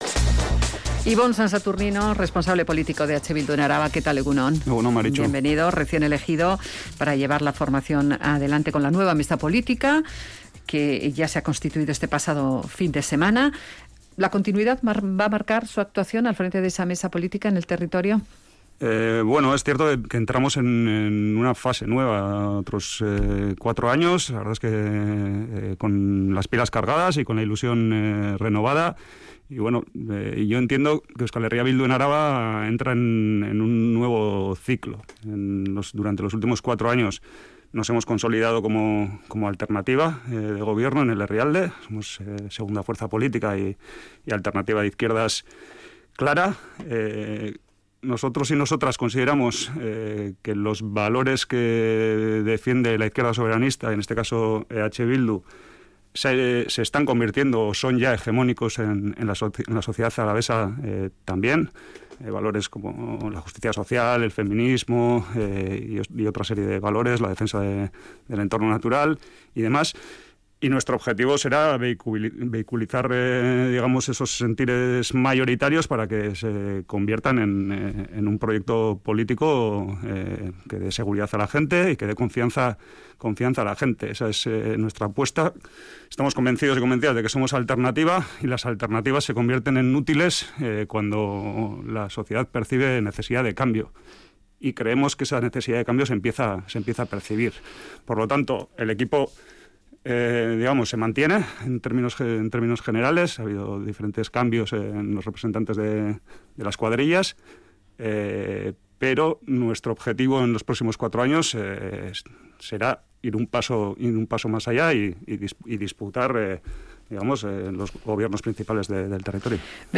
entrevistado en Radio Vitoria, asegura que su formación ha entrado en un nuevo ciclo en el que "somos alternativa"